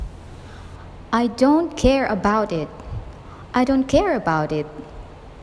far⌒away 　          faraway
care⌒about           carabout